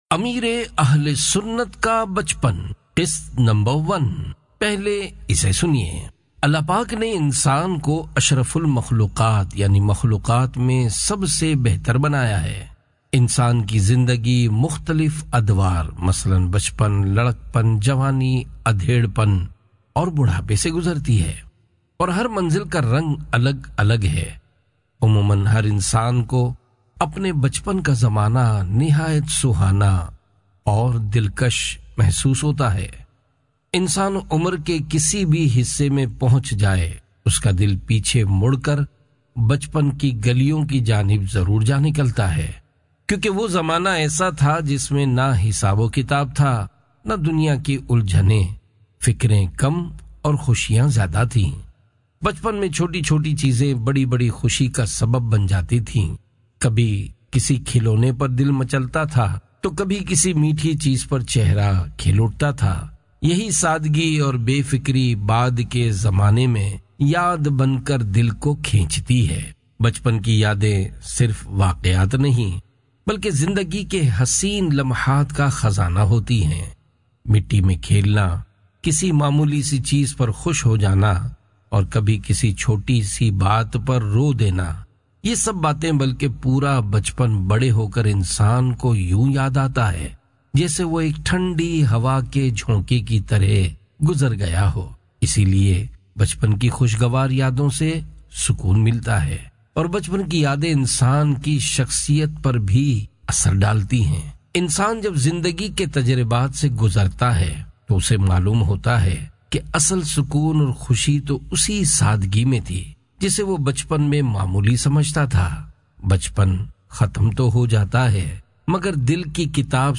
Audiobook - Ameer e Ahl e Sunnat Ka Bachpan (Urdu)